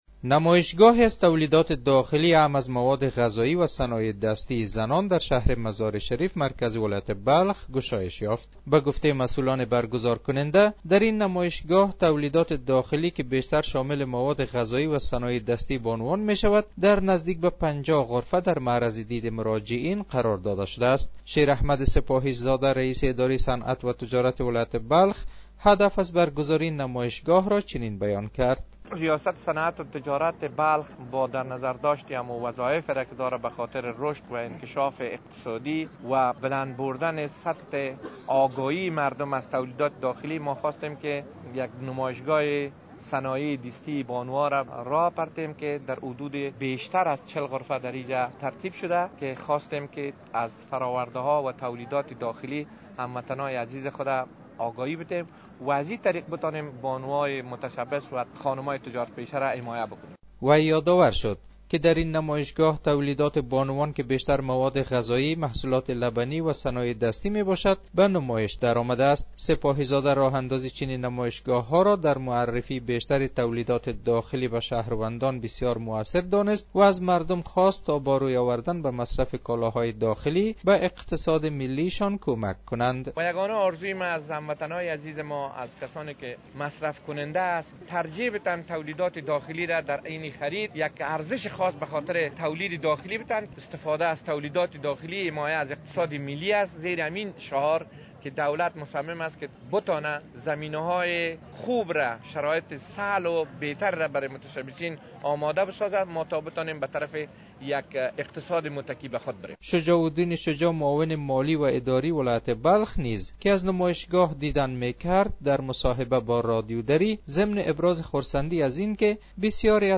گزارش : برگزاری نمایشگاه صنایع دستی بانوان در مزار شریف